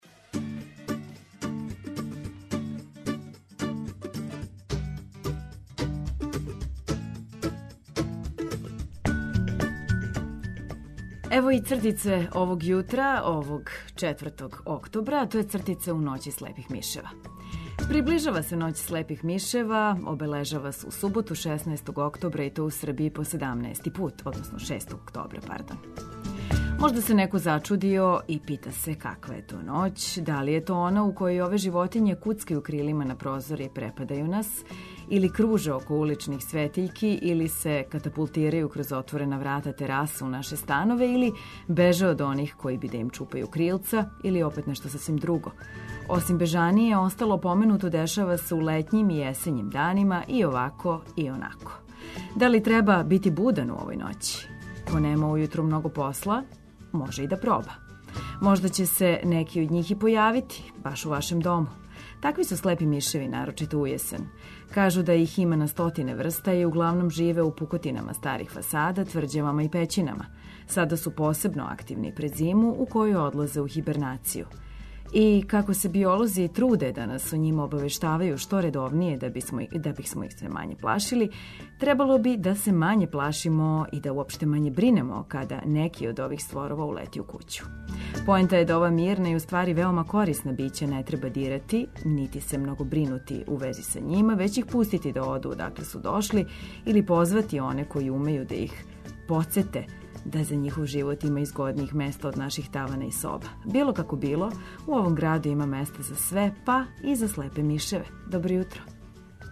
Разбудимо се заједно уз сјајну музику спремни за нове изазове.